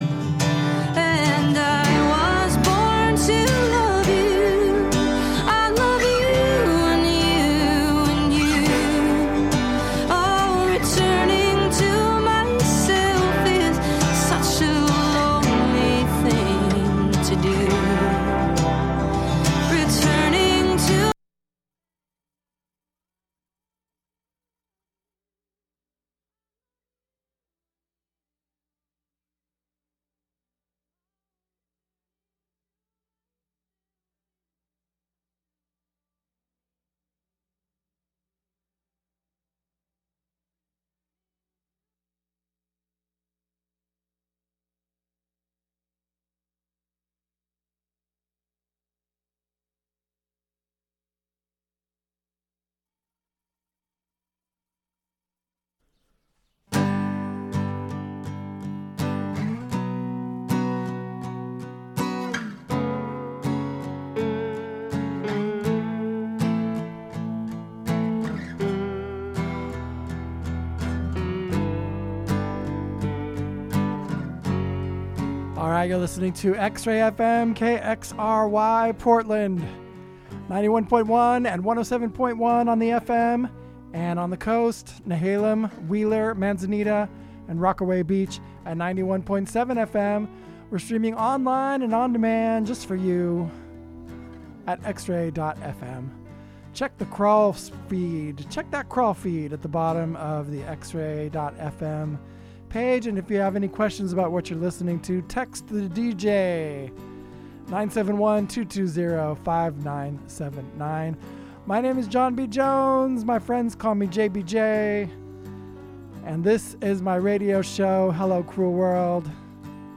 Hello Cruel World brings the worlds of singer/songwriters and acoustic music to you every Thursday from 3-4pm with conversations and in-studio performances as often as possible.